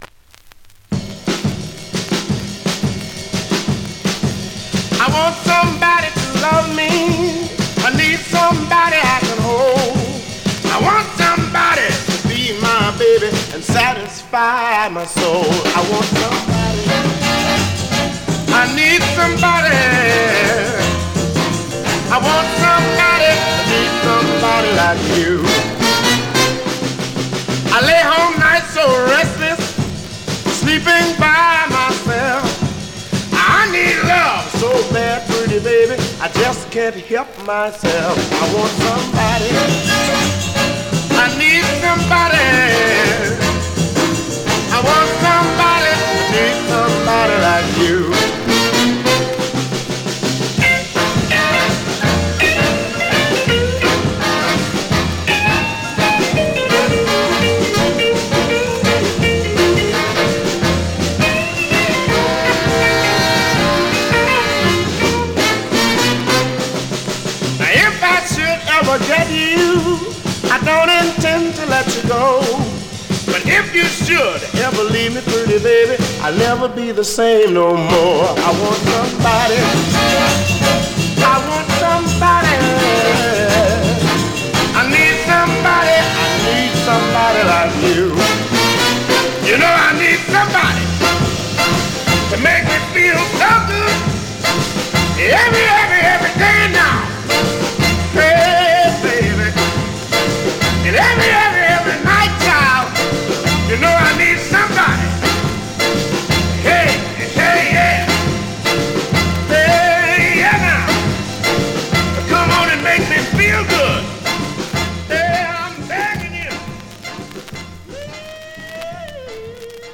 Vinyl has a few light marks plays great .
Great up-tempo Rnb dancer from this ever popular artist.
R&B